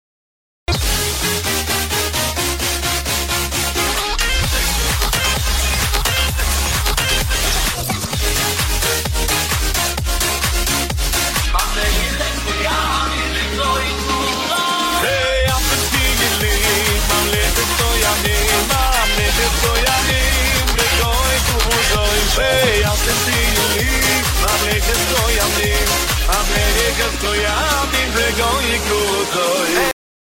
הווקאל באוקטבה גבוהה